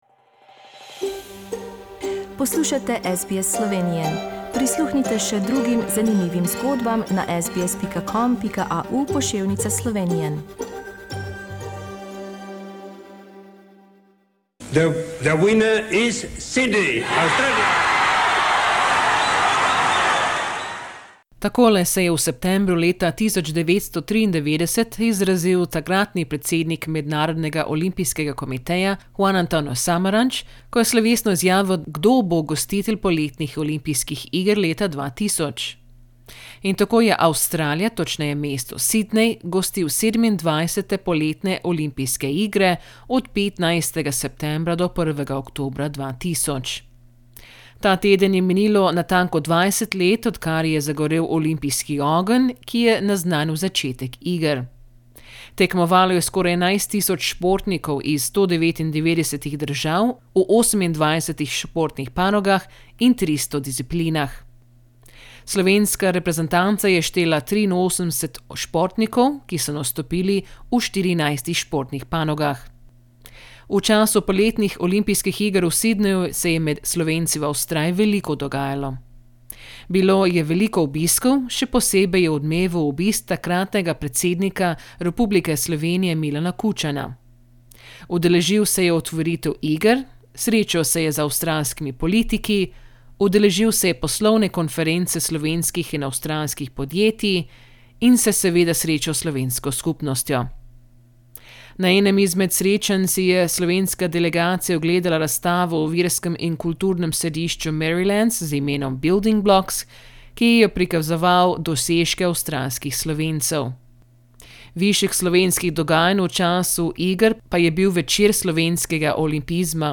A date that was especially important for Slovenia was 23rd September, 2000 when Slovenian athletes won their first gold medals for independent Slovenia. This feature takes a look back at that time and includes interviews from our program with the golden Slovenian athletes.